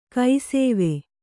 ♪ kai sēve